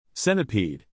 centipede ,” the stress is on the cen.)